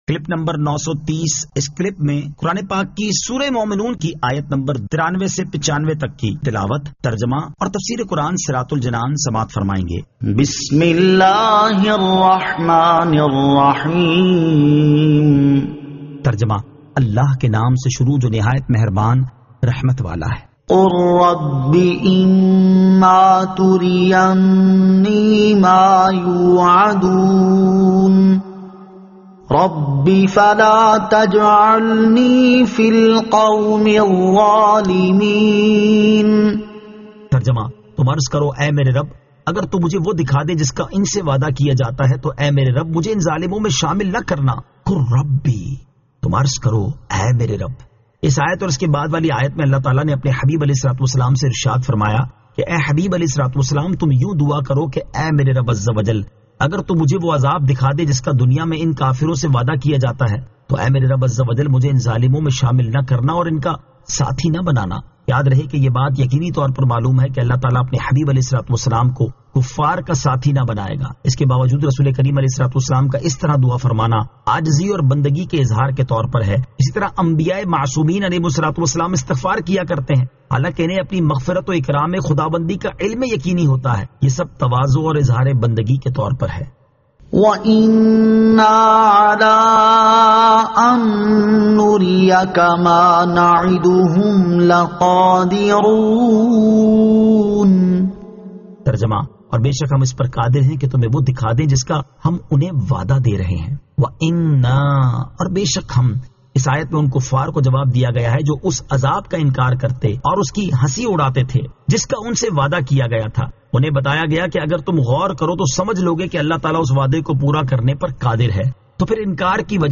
Surah Al-Mu'minun 93 To 95 Tilawat , Tarjama , Tafseer